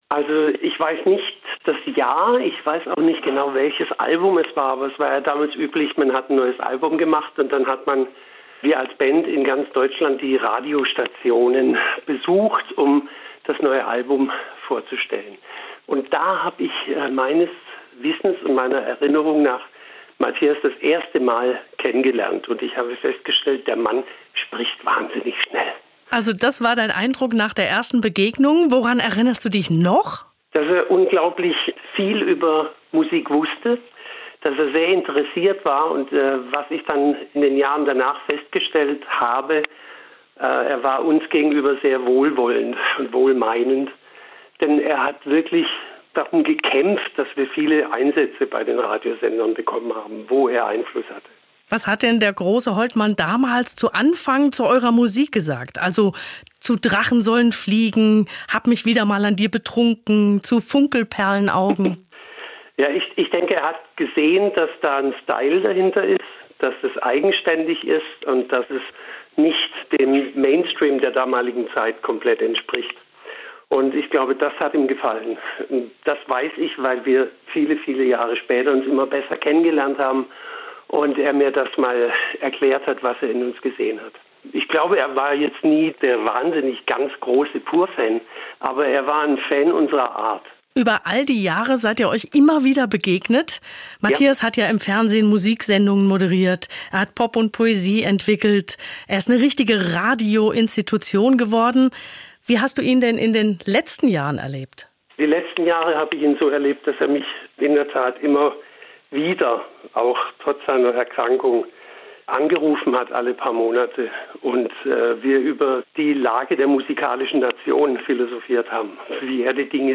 PUR Frontmann Hartmut Engler
erinnerungen-an-matthias-holtmann-von-pur-frontmann-hartmut-engler.mp3